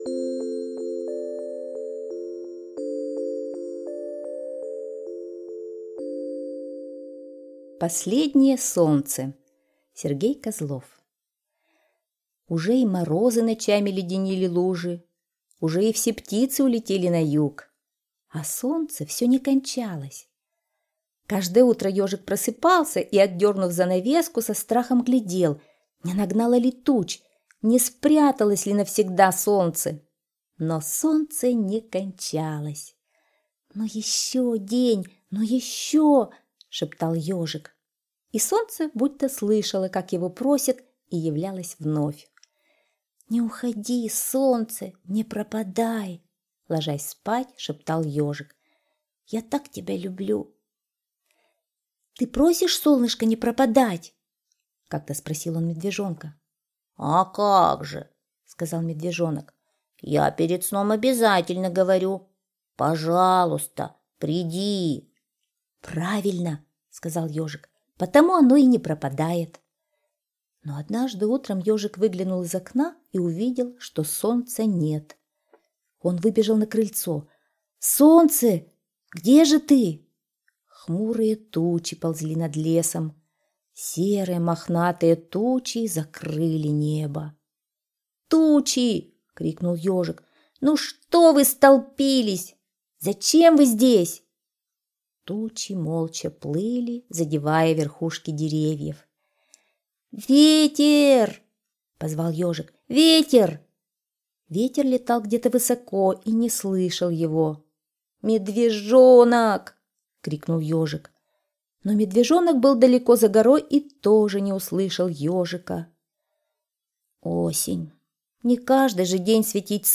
Последнее солнце – Козлов С.Г. (аудиоверсия)
Аудиокнига в разделах